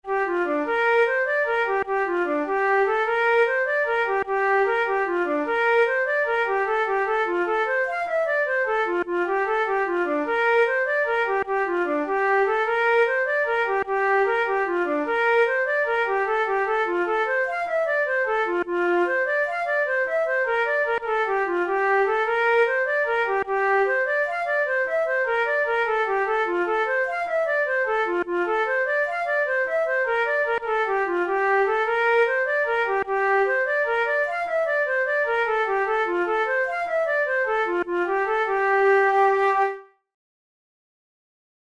InstrumentationFlute solo
KeyG minor
Time signature6/8
Tempo100 BPM
Jigs, Traditional/Folk
Traditional Irish jig